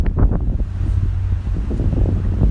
风声 " wind12
描述：风大风暴
标签： 风暴 多风
声道立体声